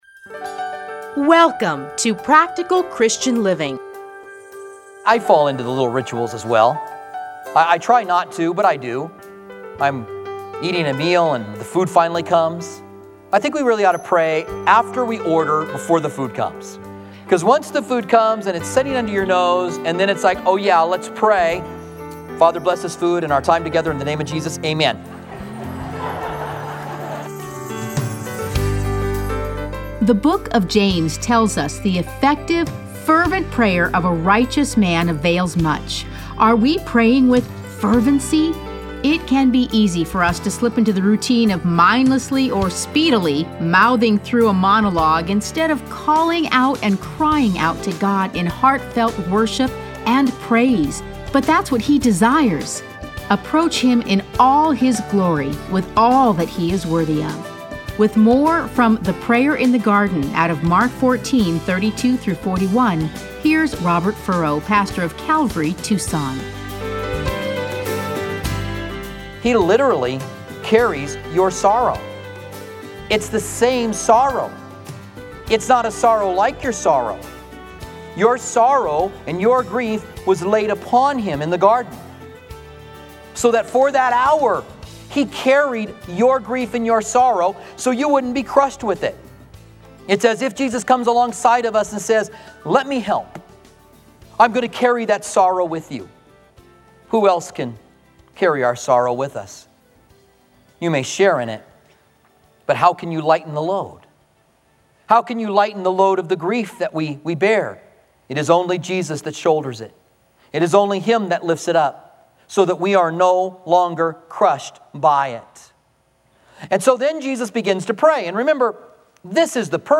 Listen to a teaching from Mark 14:32-41.